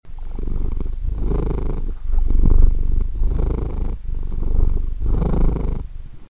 purr.mp3